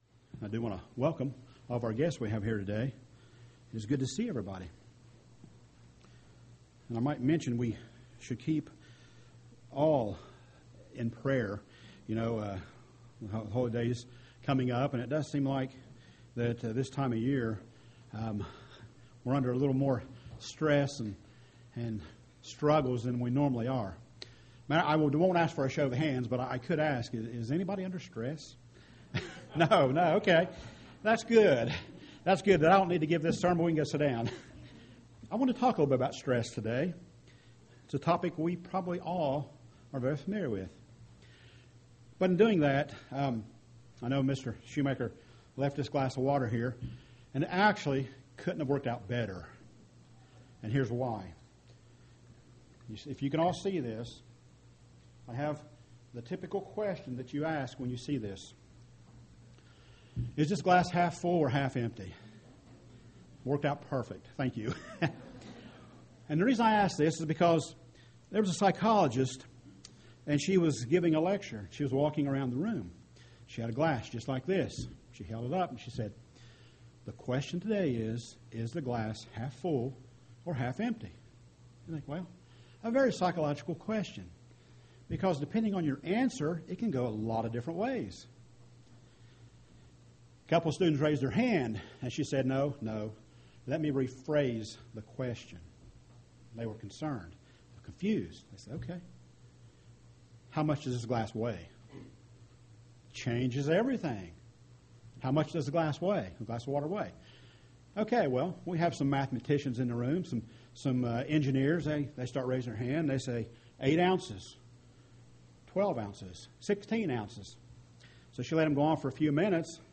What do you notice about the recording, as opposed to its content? Given in Portsmouth, OH